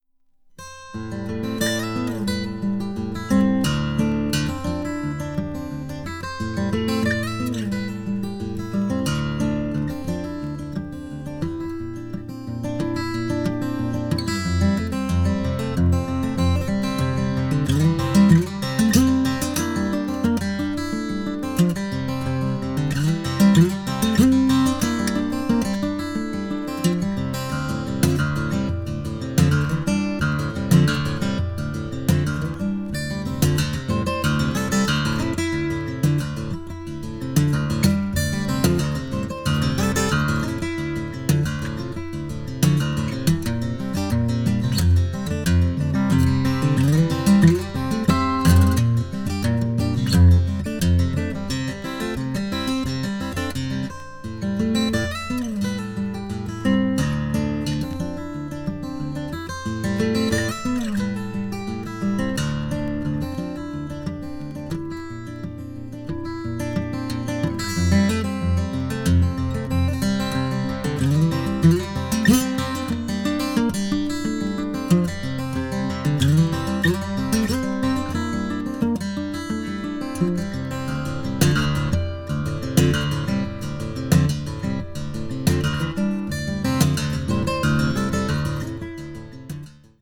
acoustic   guitar solo   neo country   neo folk   new age